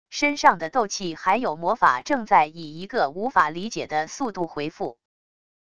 身上的斗气还有魔法正在以一个无法理解的速度回复wav音频生成系统WAV Audio Player